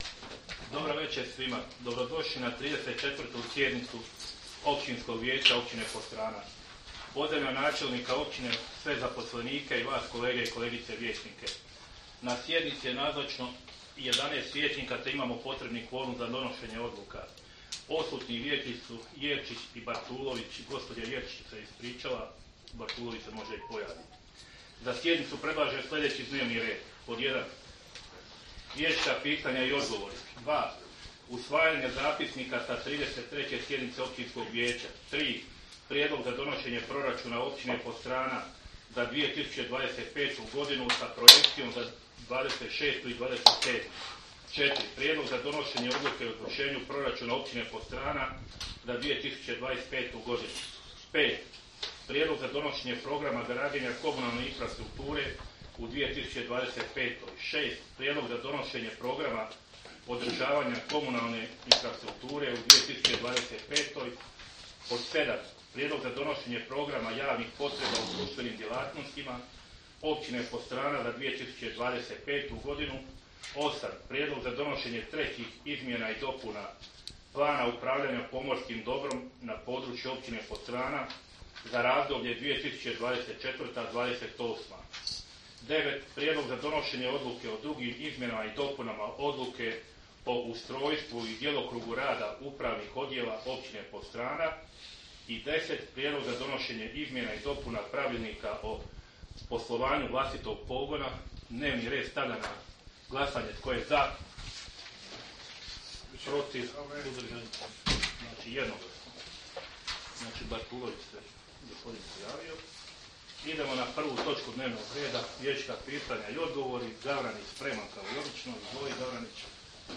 Sjednica će se održati dana 02. prosinca (ponedjeljak) 2024. godine u 18,00 sati u Vijećnici Općine Podstrana.